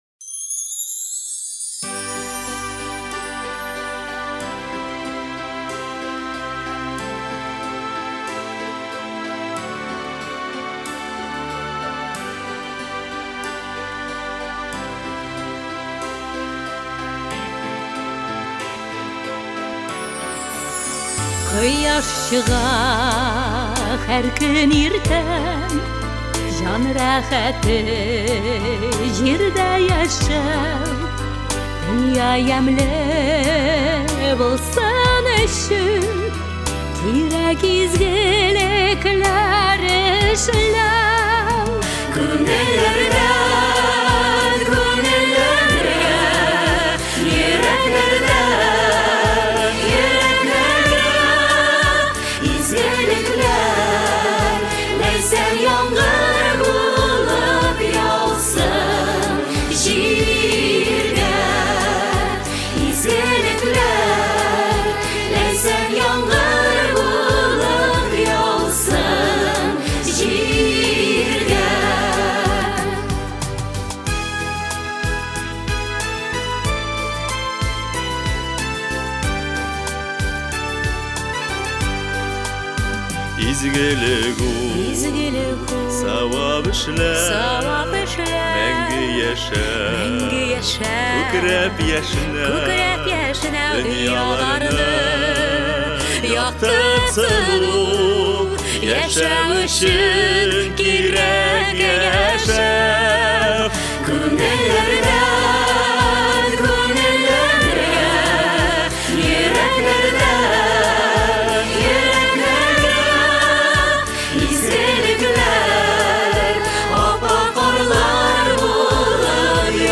13 ноябрь көнне Казанның «Әкият» курчак театрының концертлар залында әнә шулай дип аталган бик зур хәйрия концерты булды.